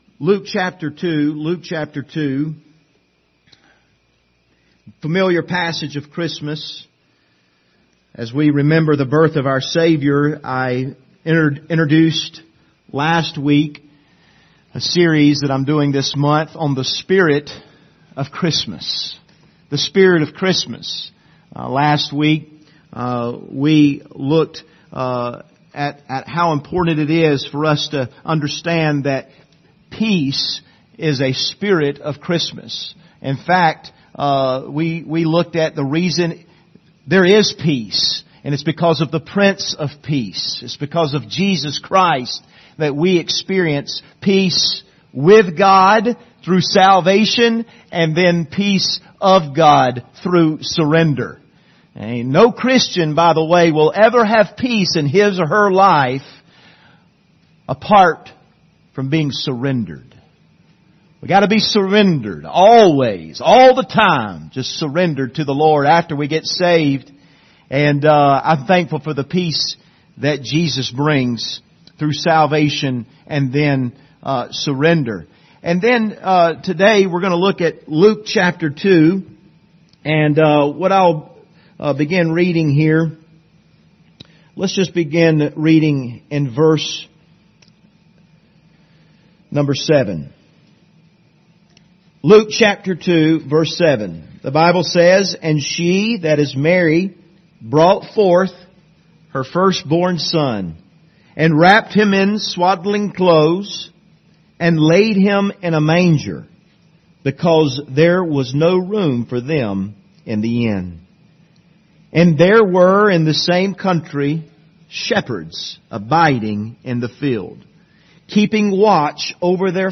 Passage: Luke 2:7-10 Service Type: Sunday Morning